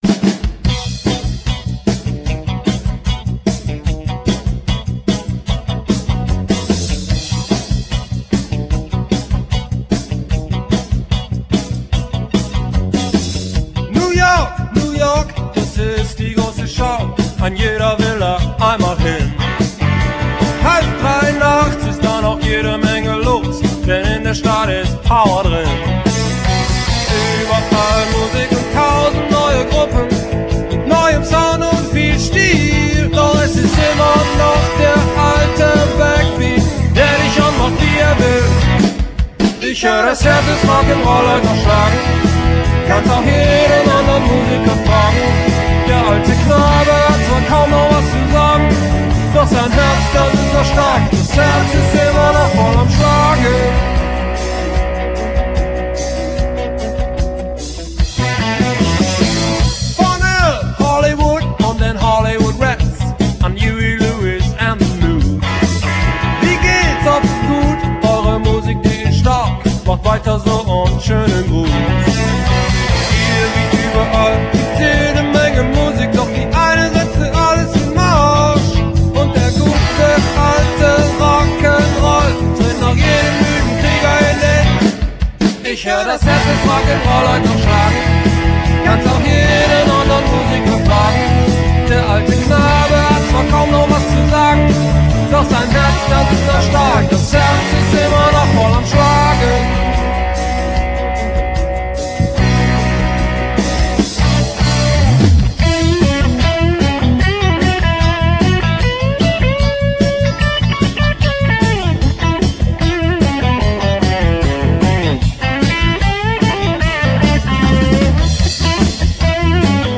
Gesang, Gitarre
Bass
Sologitarre
Schlagzeug